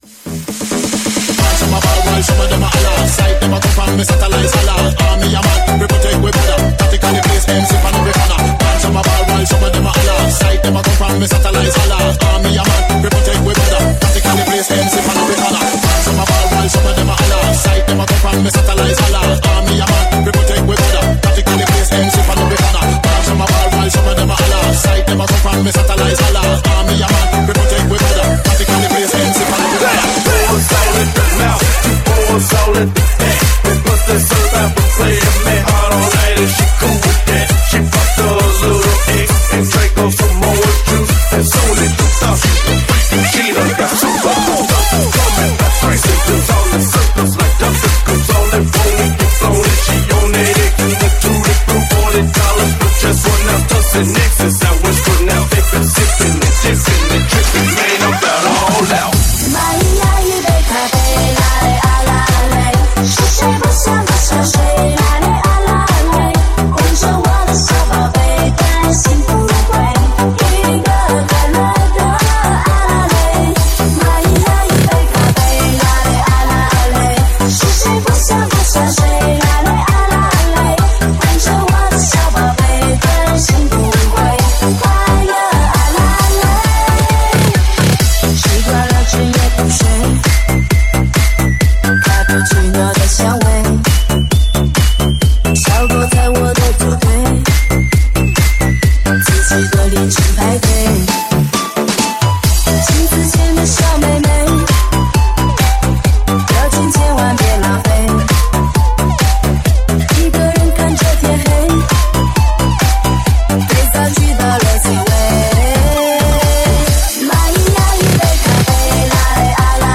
Club_Remixes_Megamix.mp3